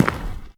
pedology_turf_footstep.4.ogg